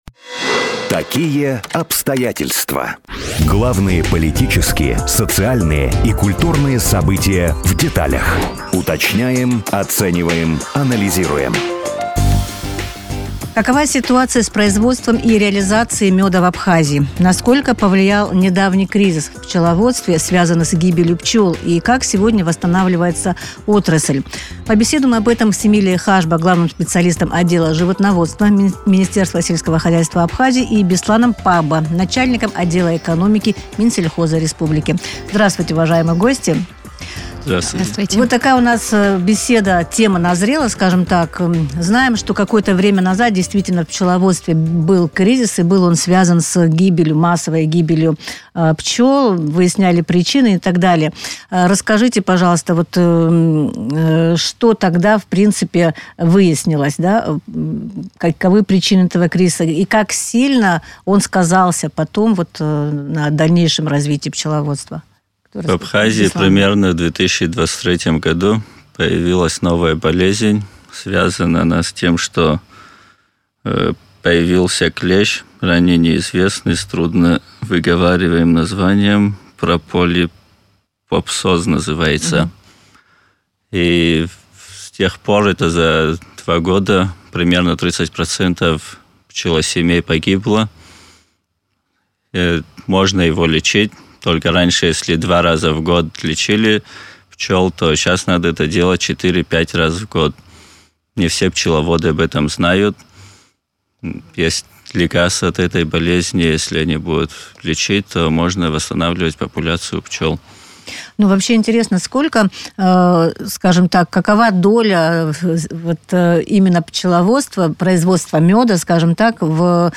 Какова ситуация с производством и реализацией меда в Абхазии, насколько повлиял кризис в пчеловодстве, связанный с гибелью пчел, и как сегодня восстанавливается отрасль, в студии радио Sputnik рассказали главный специалист отдела...